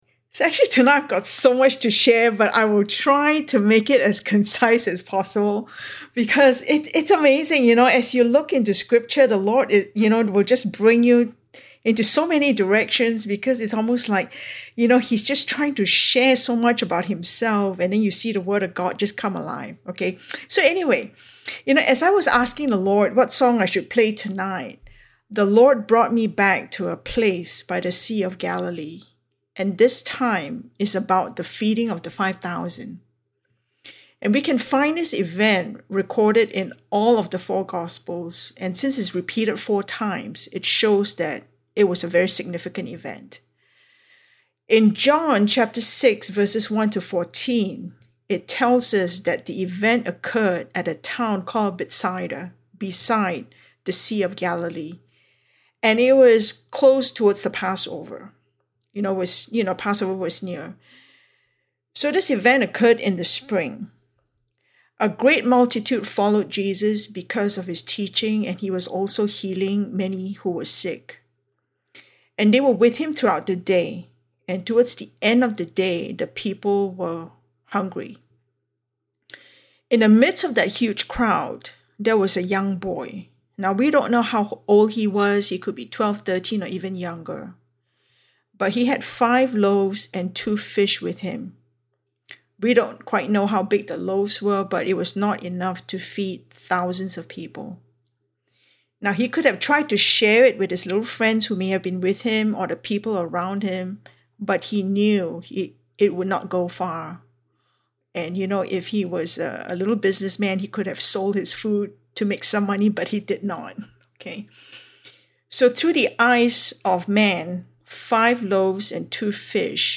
A message from the series "Spiritual Body Building."